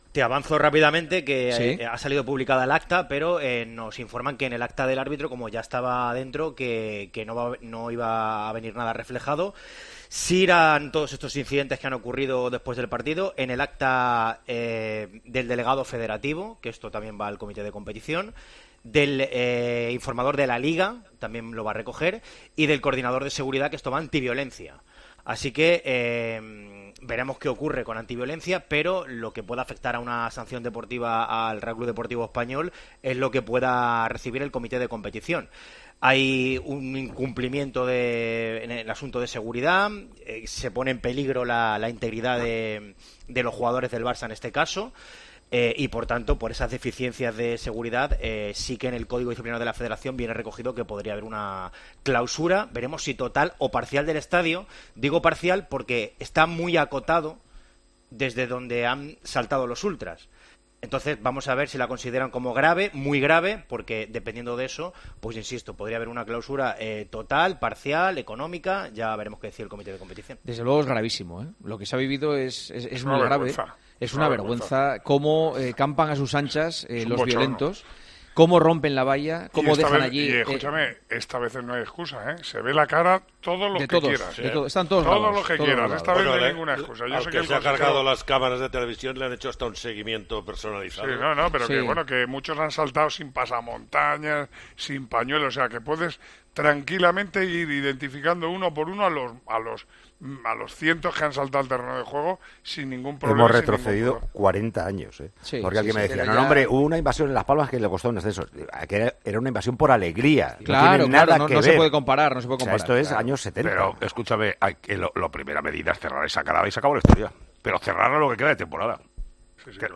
Los comentaristas de El Tertulión de los Domingos en Tiempo de Juego condenó de forma unánime lo sucedido al final del Espanyol - Barcelona: "Es una vergüenza".
Con Paco González, Manolo Lama y Juanma Castaño